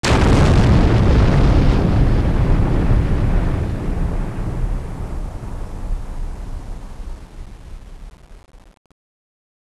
Explosion_Large_SE.wav